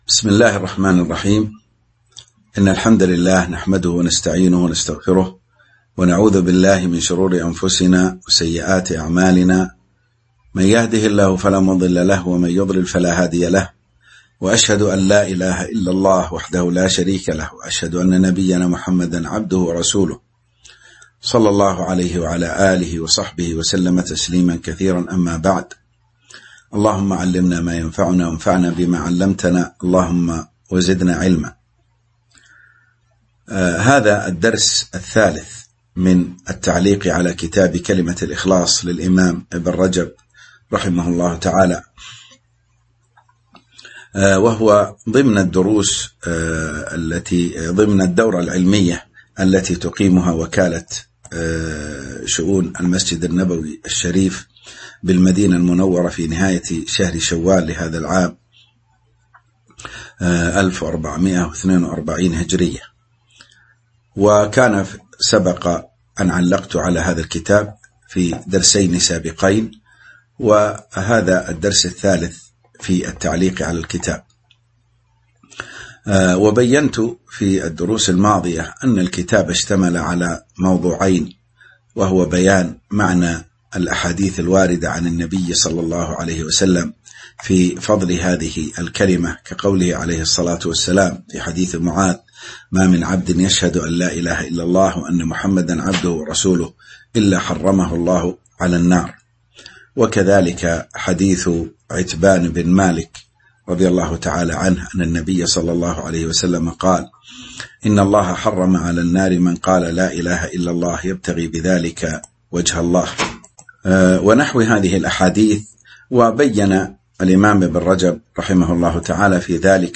تاريخ النشر ٤ ذو القعدة ١٤٤٢ هـ المكان: المسجد النبوي الشيخ